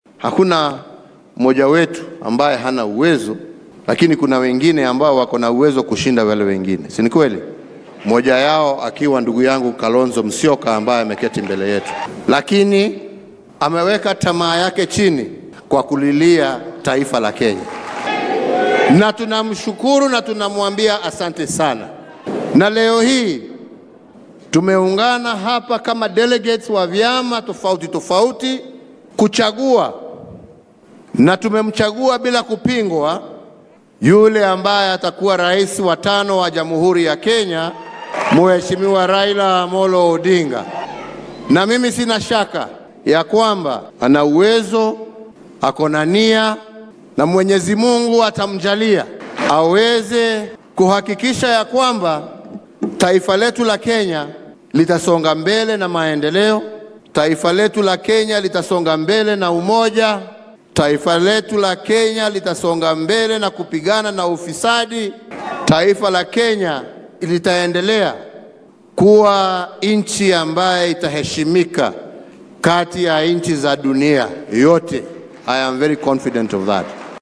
Madaxweynaha dalka Uhuru Kenyatta ayaa xarunta shirarka caalamiga ee KICC ee magaalada Nairobi shaaca kaga qaaday in shakhsiga kala wareegi doono hoggaanka ugu sarreeya wadanka uu noqon doono hoggaamiyaha isbeheysiga hiigsiga mideynta ee Azimio La Umoja Raila Odinga marka ay Kenya doorasho guud ka dhacdo 9-ka bisha Siddeedaad ee sanadkan.